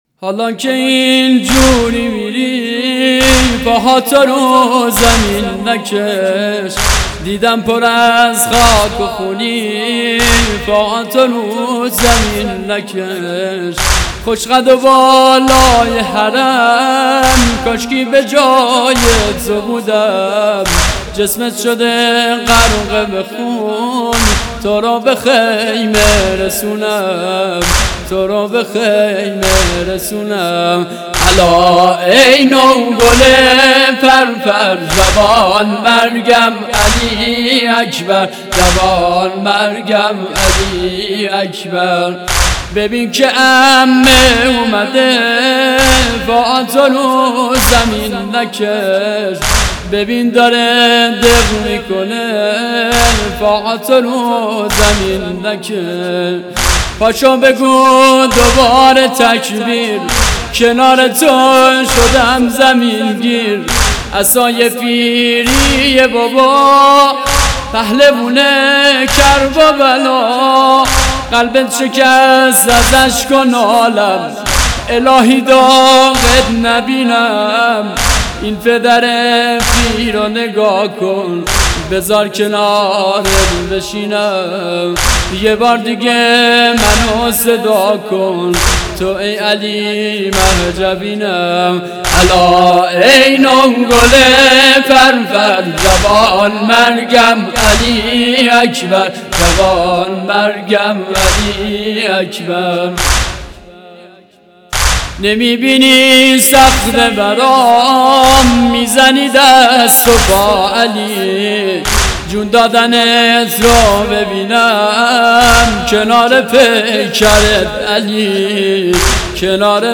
نوحه محرم 97